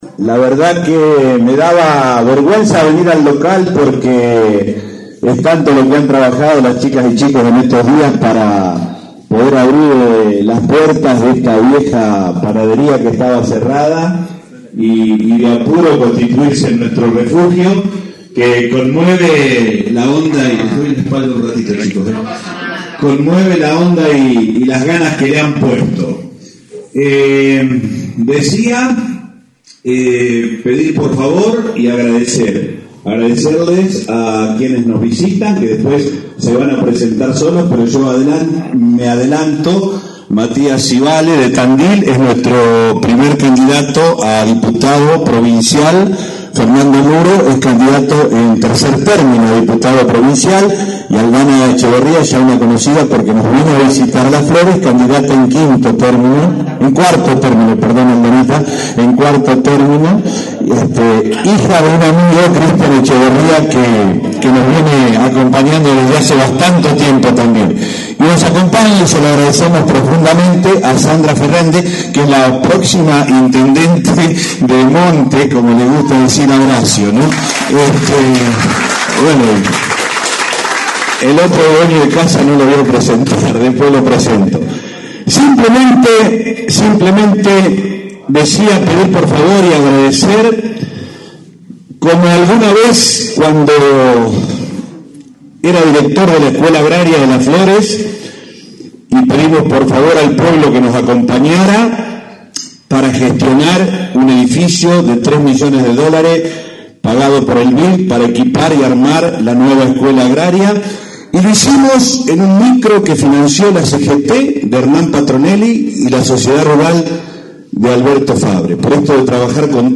Con la presencia de distintos dirigentes y pre candidatos de la 5ta sección electoral, se inauguró el bunker electoral en la ex panadería Chateláin en calle San Martin al 146.